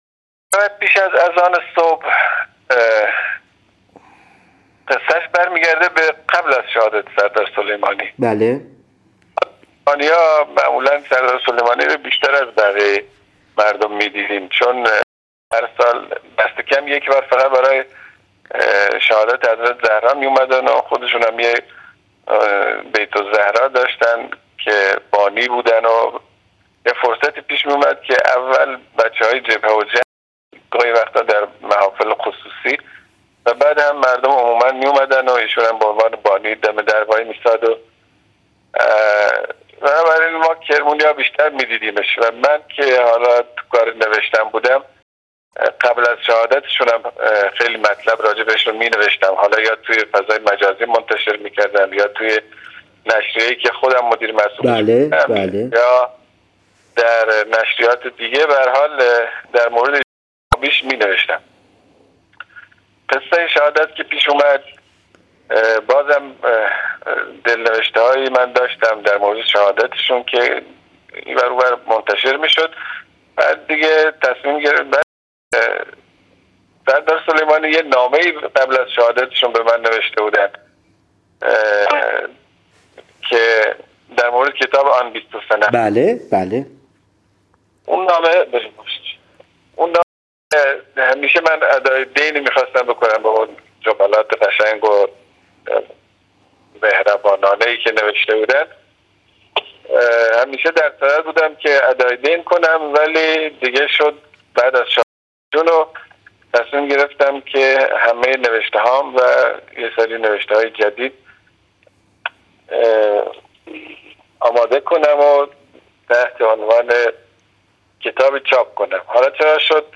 مصاحبه مناسبت شهادت حاج قاسم//// باید به نوجوانان این زمان بگوییم حاج قاسم شبیه مسئولان امروز نبود + صوت